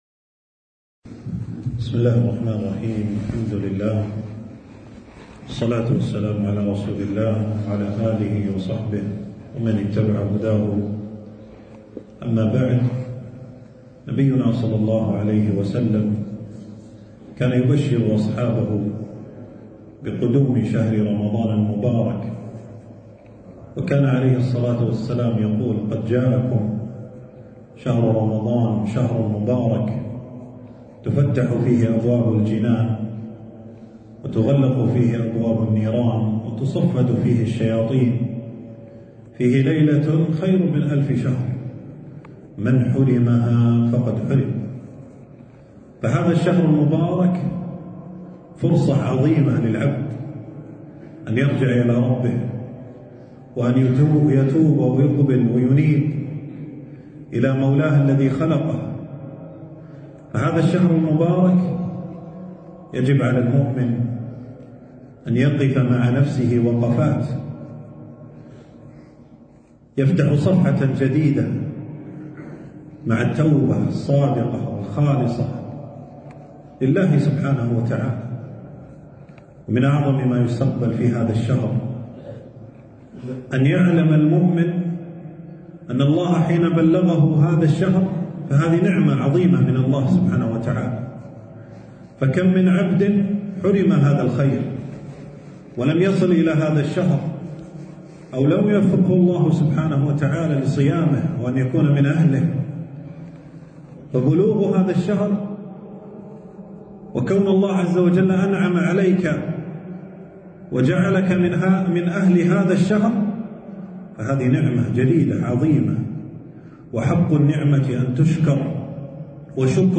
موعظة قبل رمضان الإثنين, فبراير , 2026 | 158 | طباعة الصفحة تنزيل تنزيل التفريغ محاضرة بعنوان: موعظة قبل رمضان.
في مسجد السعيدي - بمدينة الجهراء.